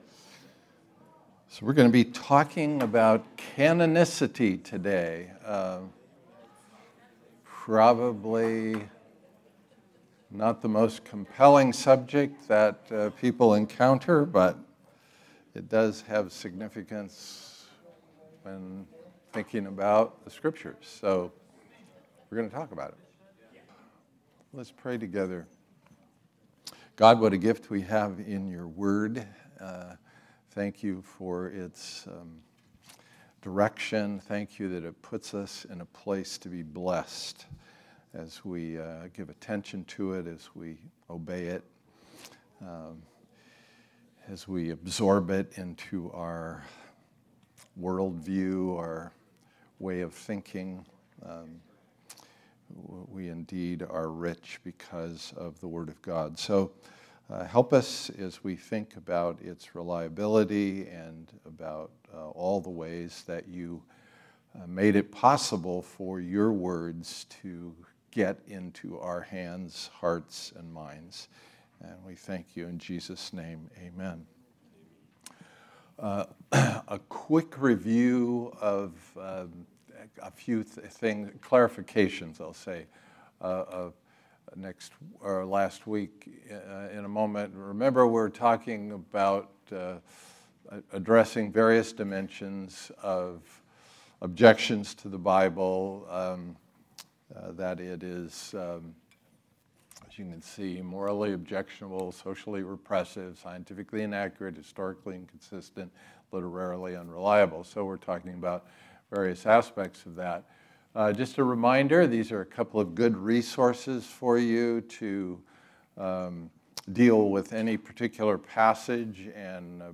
2023 Series: Trustworthy Type: Sunday School